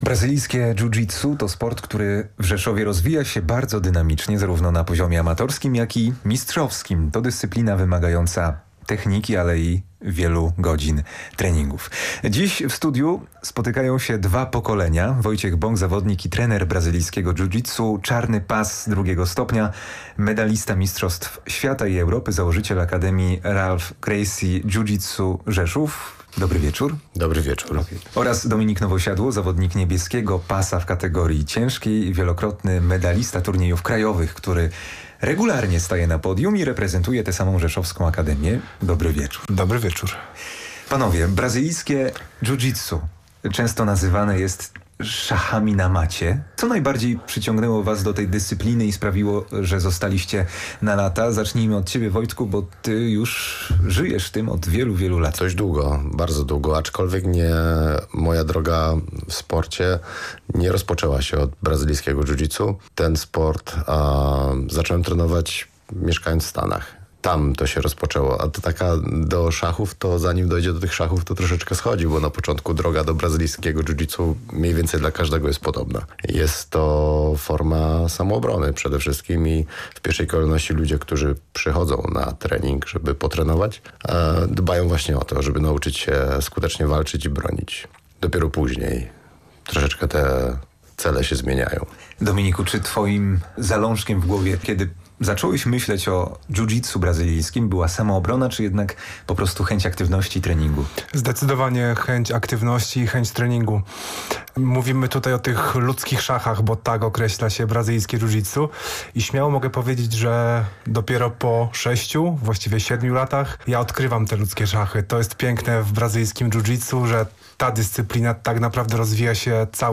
Sport • W „Sportowych Taktach” rozmowa zeszła na matę.